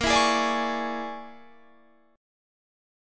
Listen to Bb7#9b5 strummed